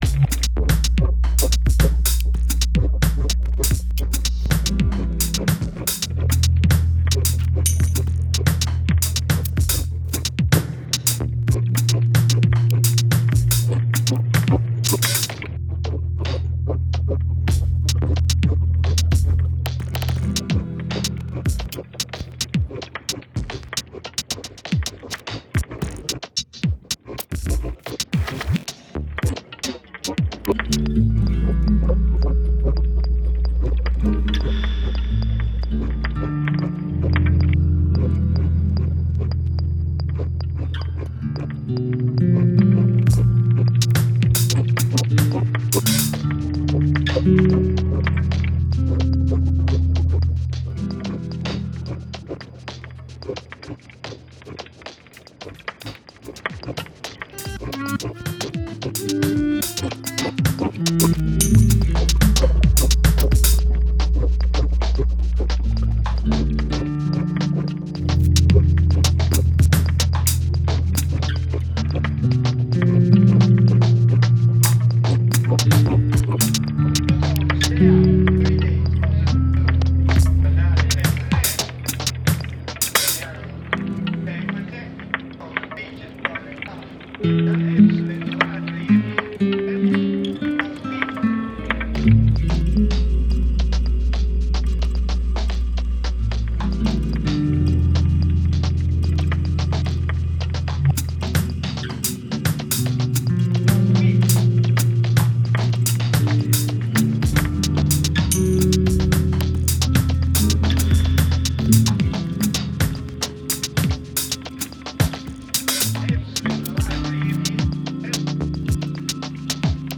some delayed acoustic guitar loops